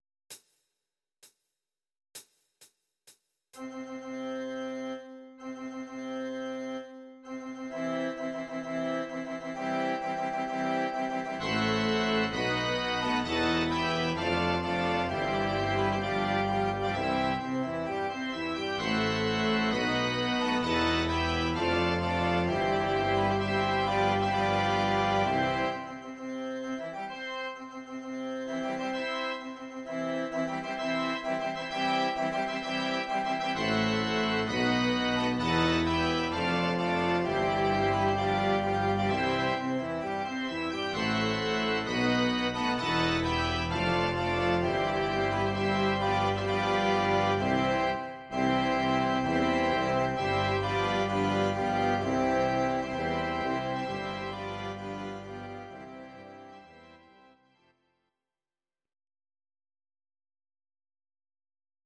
Audio Recordings based on Midi-files
Classical, Instrumental